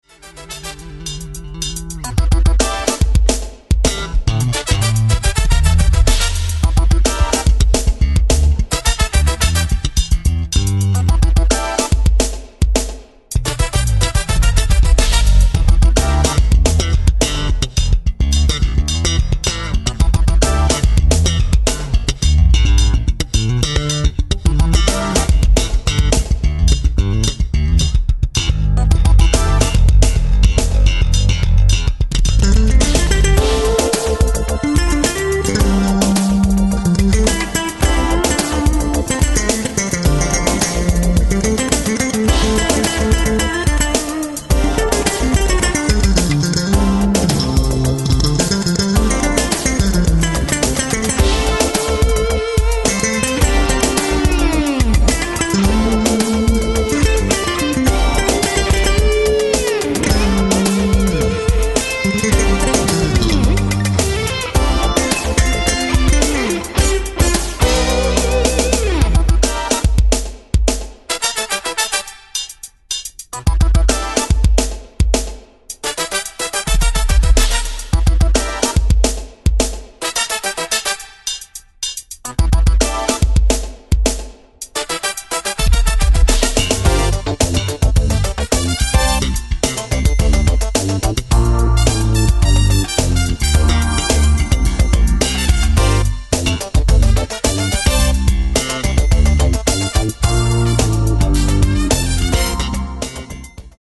bass, keyboards
trumpet
guitar